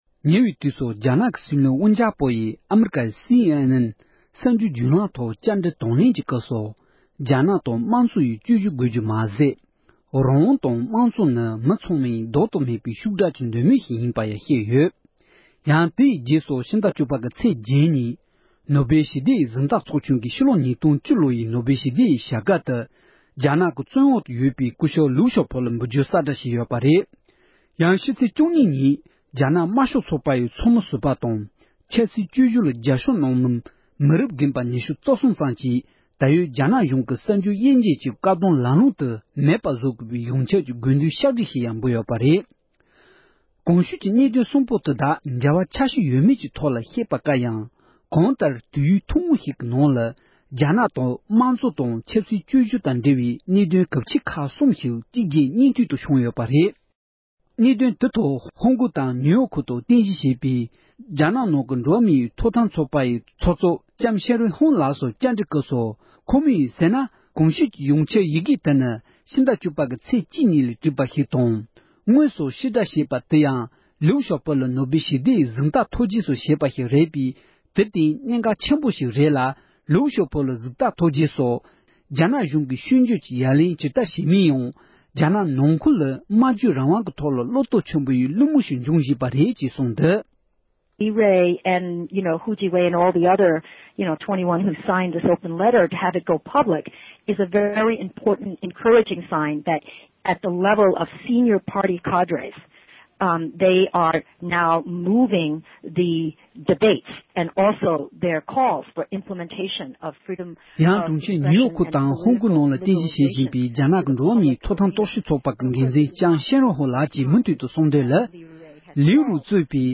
རྒྱ་ནག་དང་འབྲེལ་བའི་གནད་དོན་ཁག་གི་ཐད་བཅར་འདྲི།